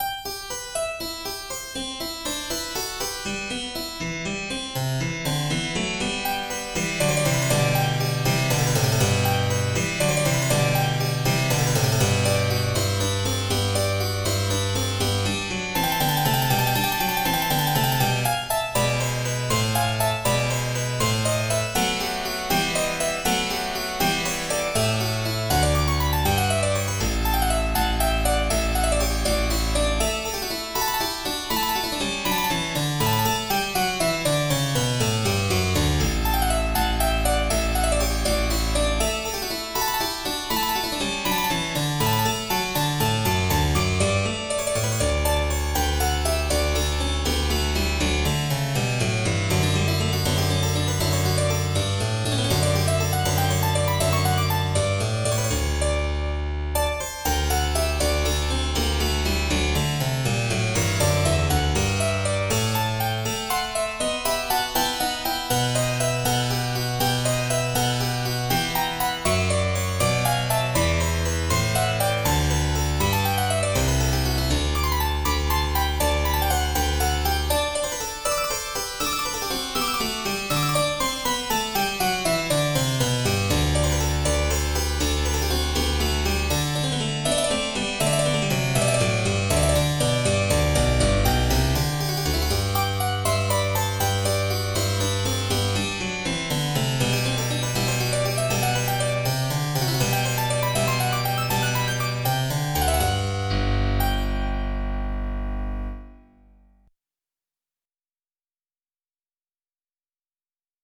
in G Major: Allegretto